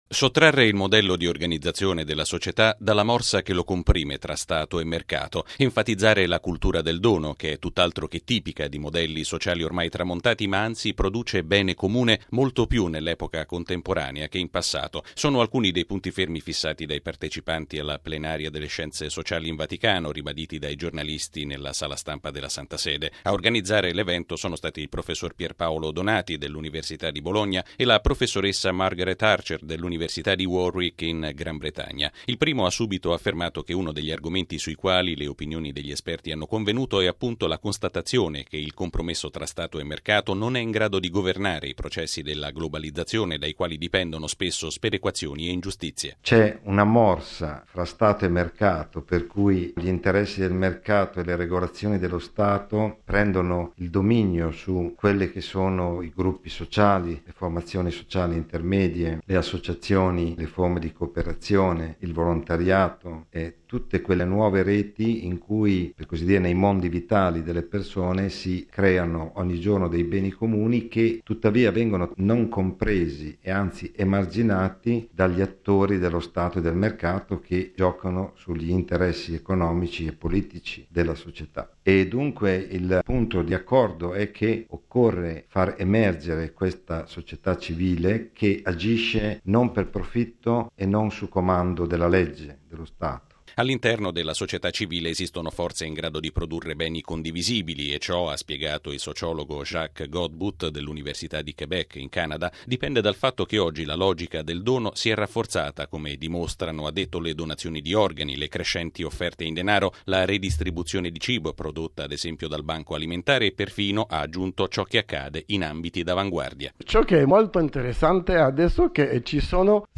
Le conclusioni dei lavori sono state presentate stamattina nella Sala Stampa della Santa Sede. Il servizio